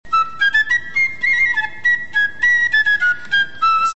Often accompanied by a drum, it's the instrument heard